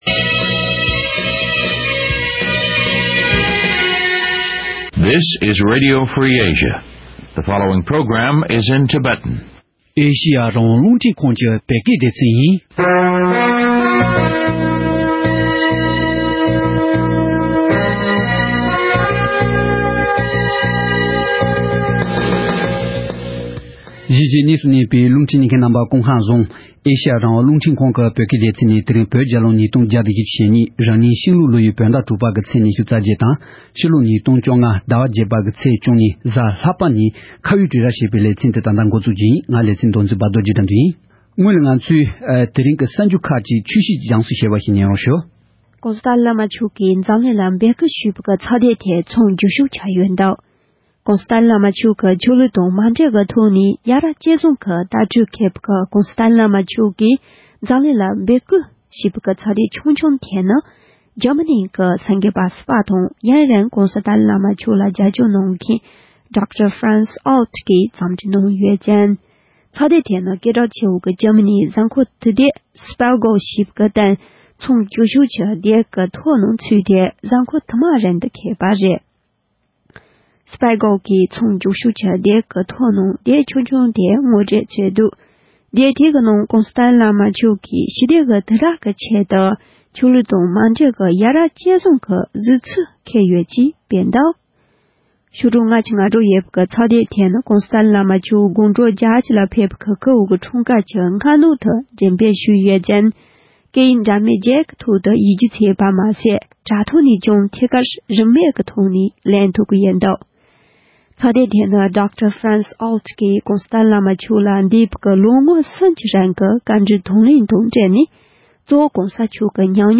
༄༅། །ཐེངས་འདིའི་ཁ་བའི་གྲོས་རྭ་ཞེས་པའི་ལེ་ཚན་ནང་། སྐབས་བཅུ་དྲུག་པའི་བོད་མི་མང་སྤྱི་འཐུས་ཀྱི་འོས་མིར་ལངས་རྒྱུ་ཡིན་པའི་གསལ་སྒྲགས་སྤེལ་མཁན་མདོ་སྟོད་ཆོལ་ཁའི་ཁོངས་སུ་གཏོགས་པའི་འོས་མི་གསར་པ་གསུམ་དང་ད་ཡོད་སྤྱི་མཐུས་ལས་ཐོག་གཅིག་བཅས་ཁོང་རྣམས་པར་སྤྱི་འཐུས་འོས་མིར་ལང་རྒྱུའི་དགོས་པ་དམིགས་ཡུལ་སྐོར་གླེང་མོལ་ཞུས་པ་ཞིག་གསན་རོགས་གནང་།།